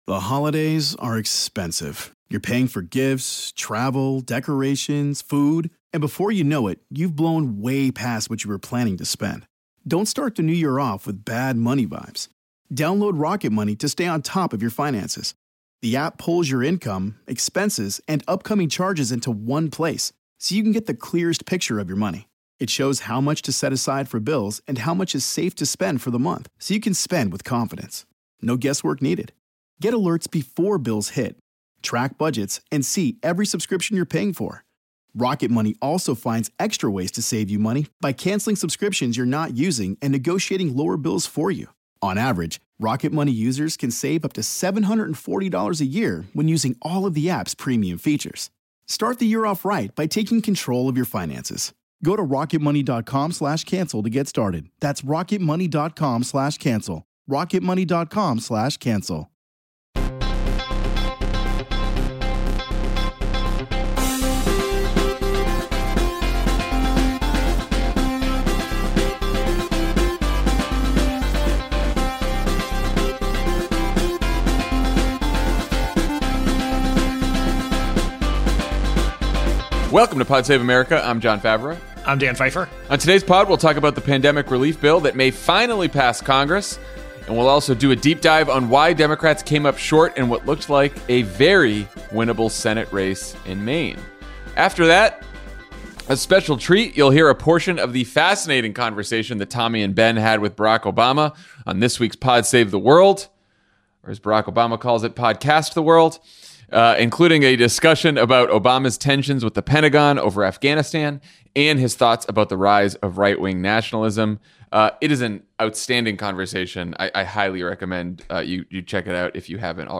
Mitch McConnell agrees to stimulus checks because he’s worried about losing Georgia, Covid relief nears the finish line, and Democrats ponder why they lost the Senate race in Maine. Then, in an excerpt of his interview on this week’s Pod Save the World, Barack Obama talks to Tommy and Ben about his tensions with the Pentagon over Afghanistan and his thoughts about the rise of right-wing nationalism.